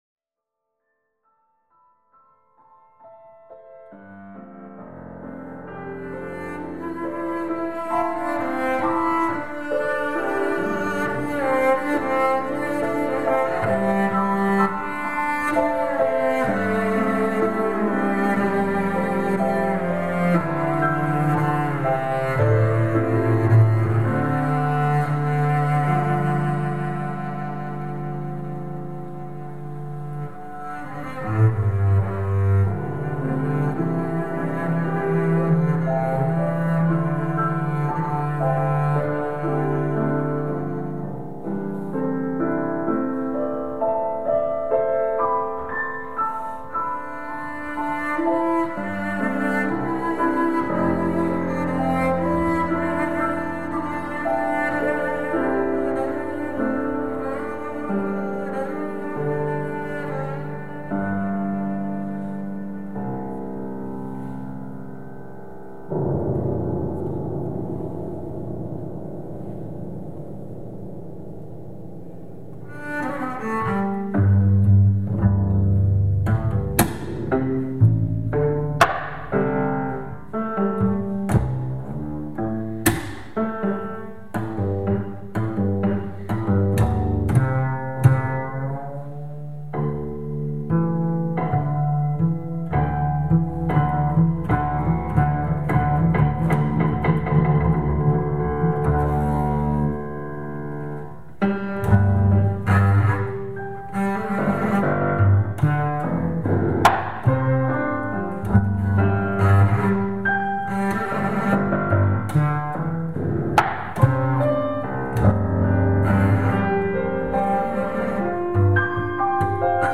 for double bass and piano
The sound of the bass is full of resonance, the extended techniques on both bass and piano are idiomatic in an oriental way.